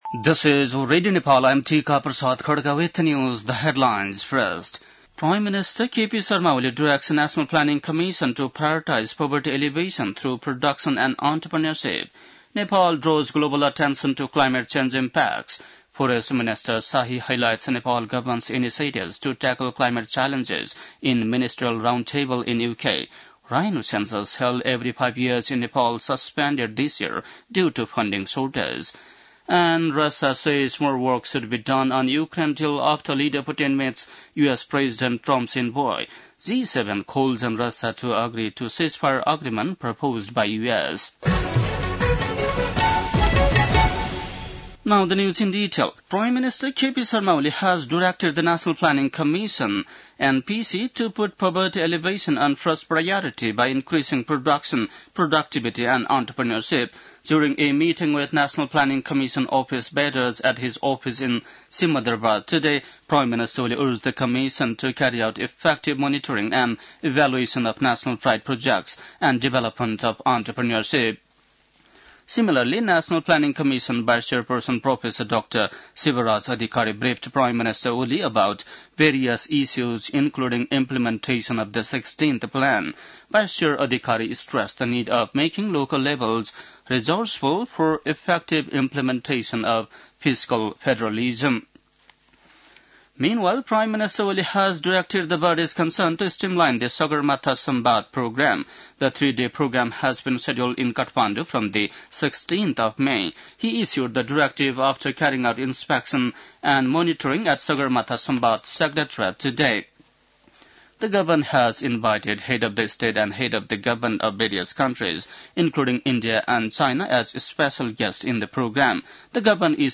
बेलुकी ८ बजेको अङ्ग्रेजी समाचार : १ चैत , २०८१